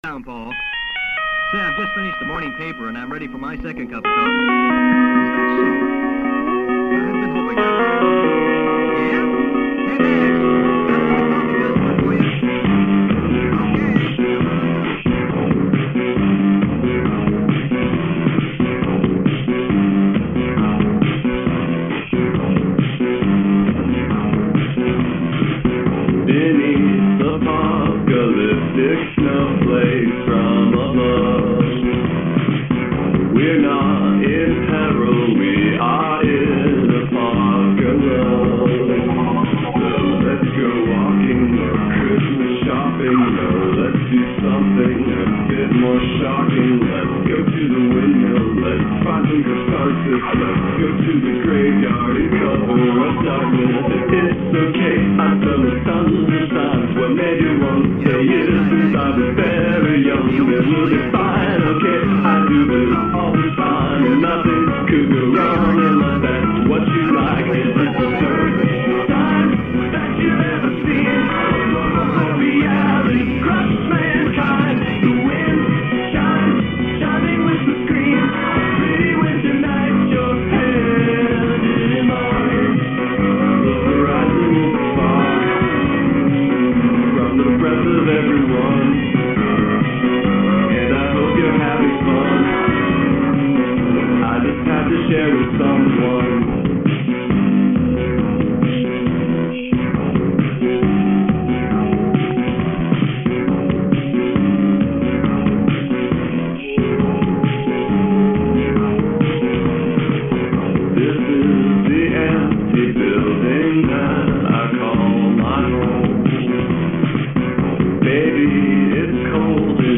If you’ve ever wondered what Aurora Borealis would sound like if it was a low-quality MP3 someone recorded off of a telephone line in the early 90′s and then traded among hardcore fans on cassettes for thirty years, wonder no more.